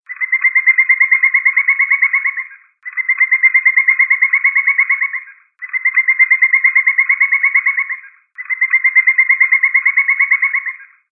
Wood pecker sound ringtone free download
Animals sounds